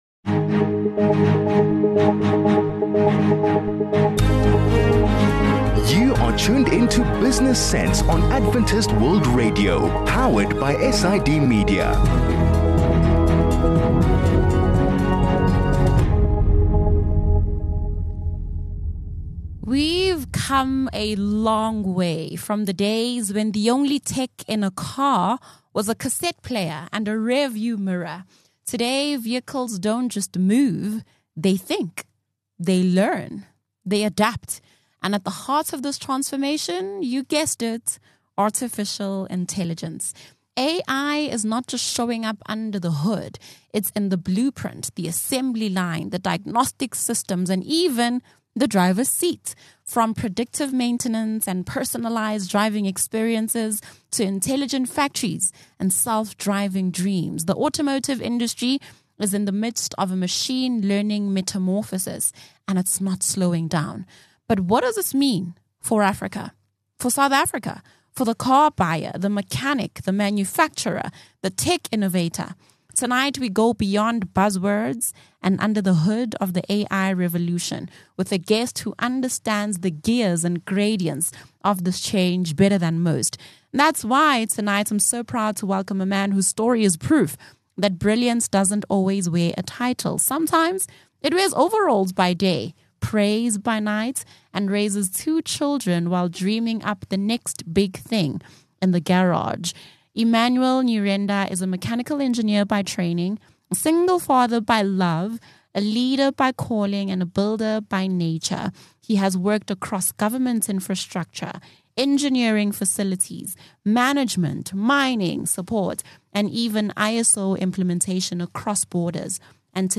View Promo Continue Adventist World Radio SIDmedia Install Business Sense 24 Jun Tech/Innovation | AI in Automotive Industry 46 MIN Download (21.7 MB) A conversation on how AI is changing cars and how they're made.